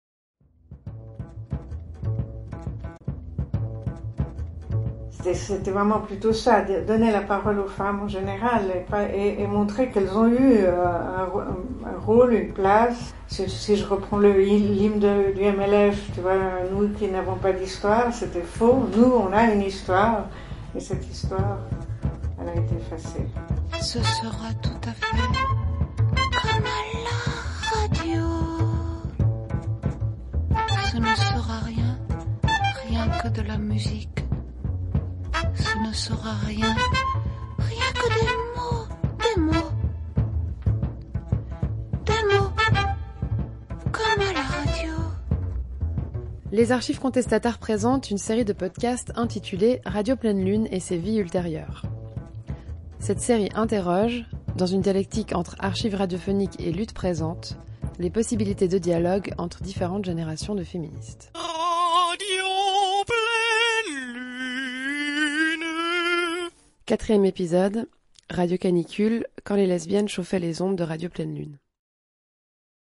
Documentaire radiophonique FR DE Untertitel du Collectif Notre histoire compte avec des animatrices de l’émission de Radio « Radio Canicule ». 2022, 34 min.
Dieser Podcast taucht mit Ausschnitten, Erzählungen und Erinnerungen der einstigen Radiofrauen in die bewegte und bewegende Geschichte des lesbischen Radios ein.